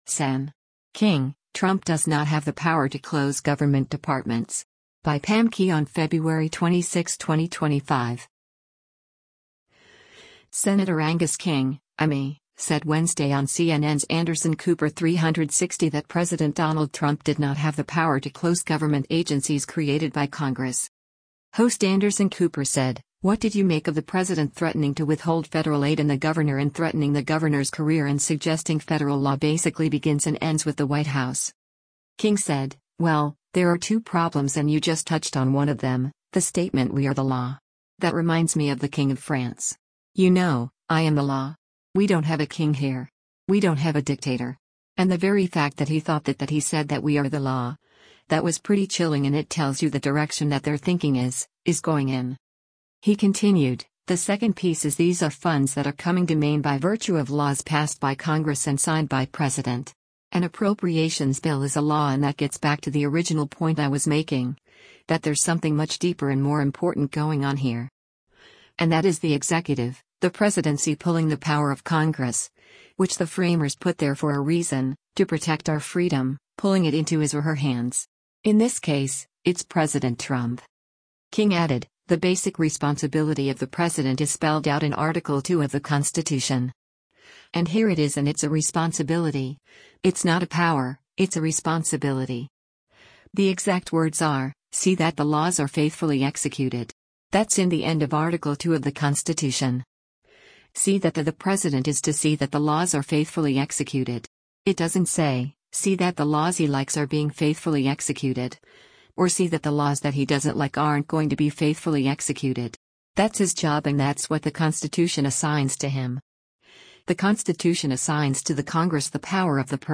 Senator Angus King (I-ME) said Wednesday on CNN’s “Anderson Cooper 360” that President Donald Trump did not have the power to close government agencies created by Congress.